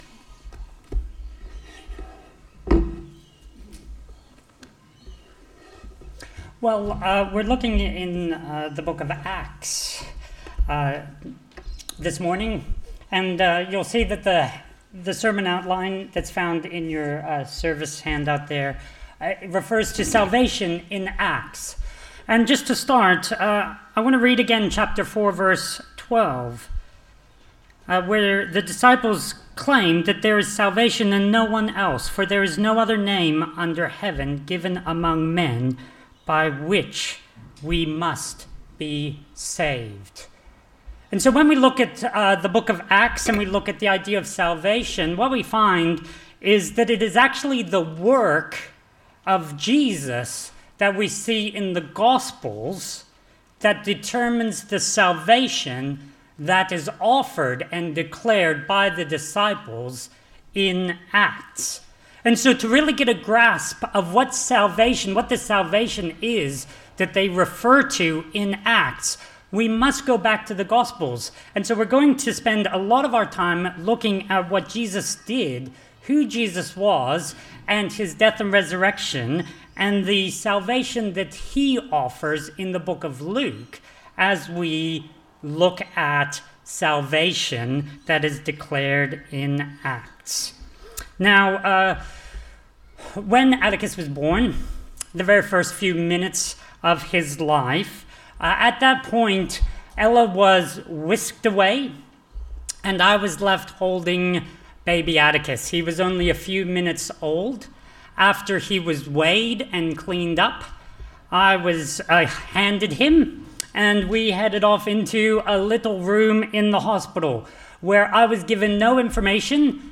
… continue reading 315 эпизодов # Christianity # Religion # Anglican # Jesus # Helensburgh # Stanwell # Park # Helensburgh Stanwell Park Anglican A Church # Stanwell Park Anglican A Church # Sermons